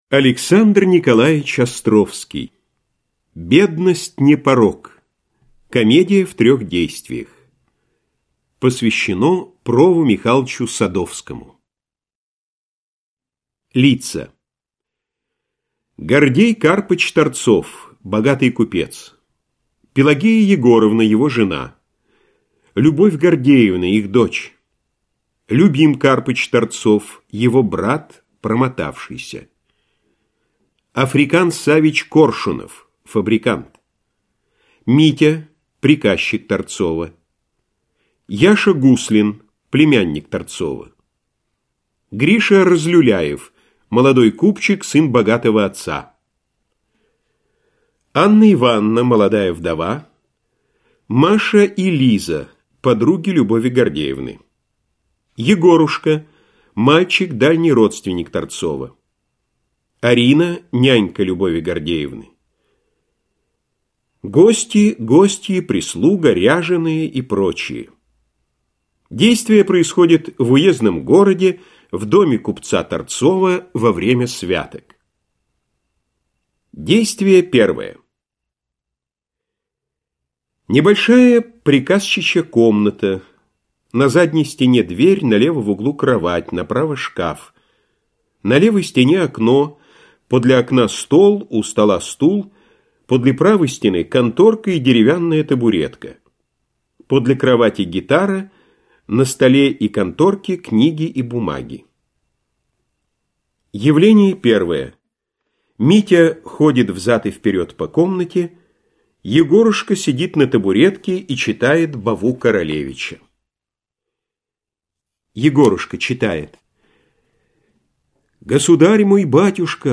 Аудиокнига Доходное место. Свои люди – сочтёмся! Бедность не порок. Лес | Библиотека аудиокниг